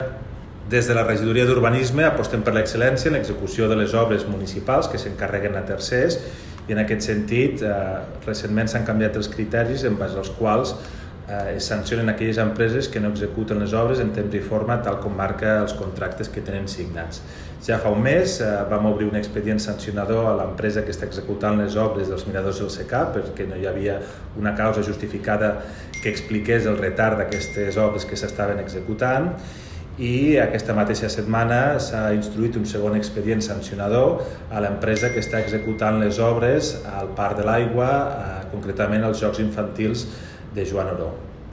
tall-de-veu-de-toni-postius-sobre-lexpedient-sancionador